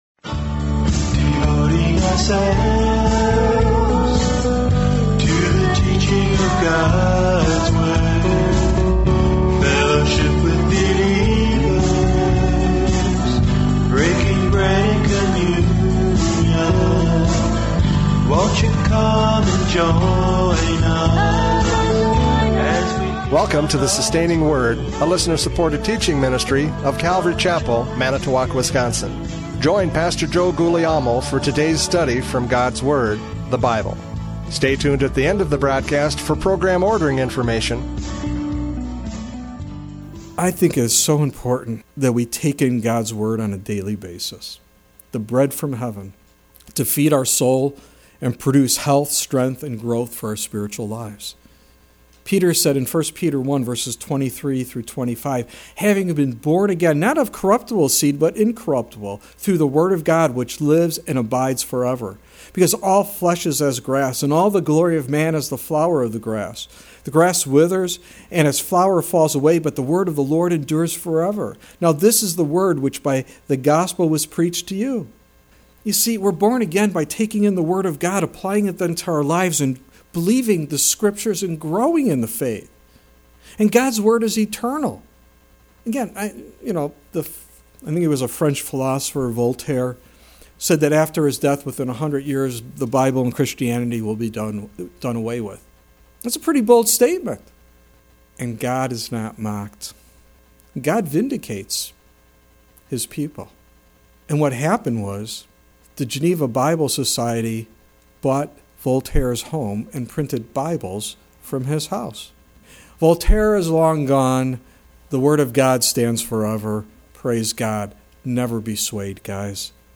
John 6:30-40 Service Type: Radio Programs « John 6:30-40 The Bread of Life!